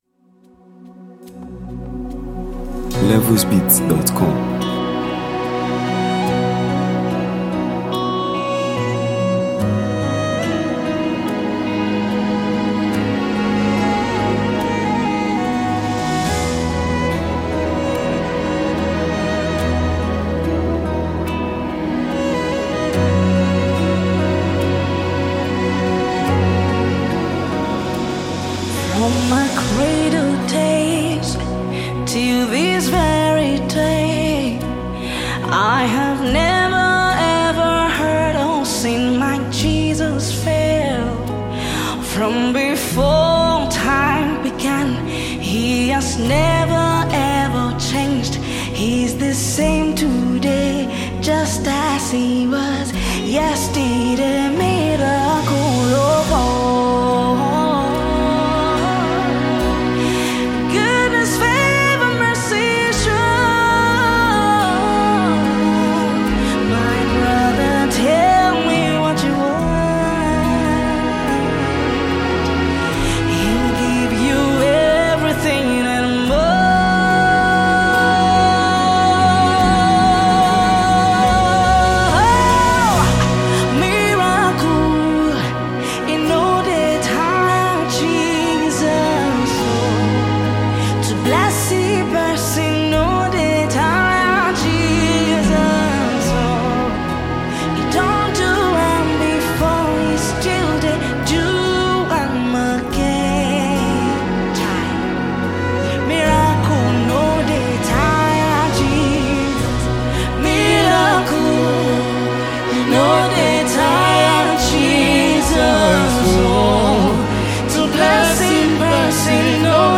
the renowned Nigerian gospel singer